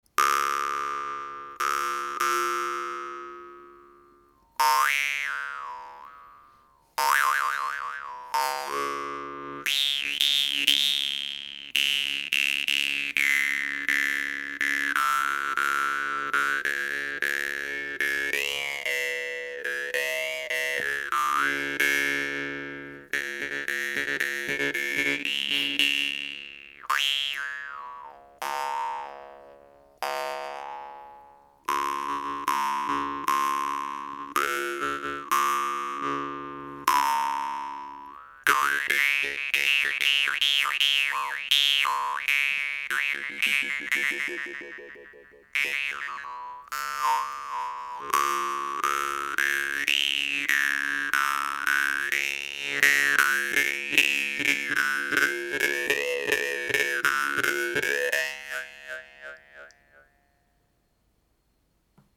UMAÏ est vraiment une guimbarde à mettre entre toutes les mains: très douce, facile à jouer, puissante, long sustain….et très jolie! Elle répond très bien à l’aspiration et à la moindre sollicitation sur la languette.